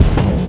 Energy Fx 08.wav